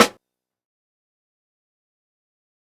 TC SNARE 01.wav